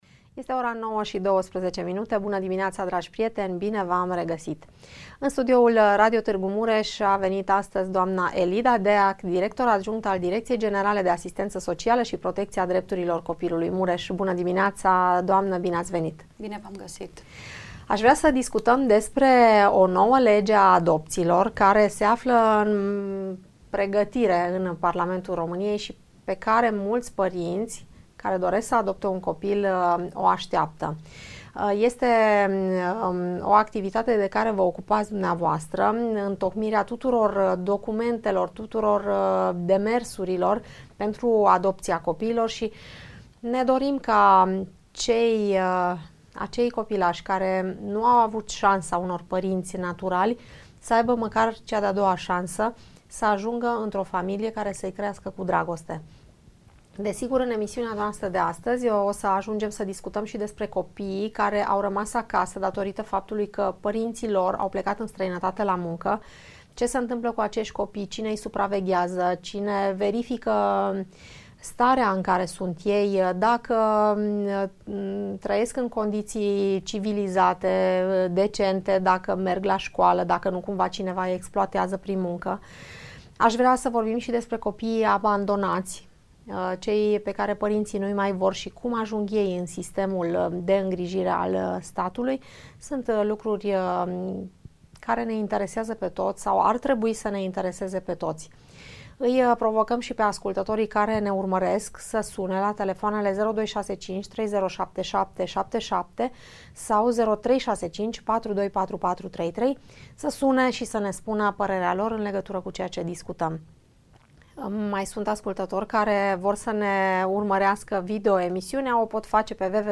Discutie